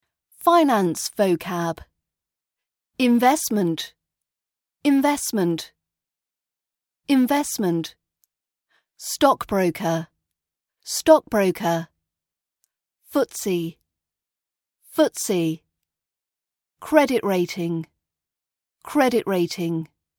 Finance Vocab - RP British Accent podcast
Practice your RP British accent pronunciation with this selection of Finance words.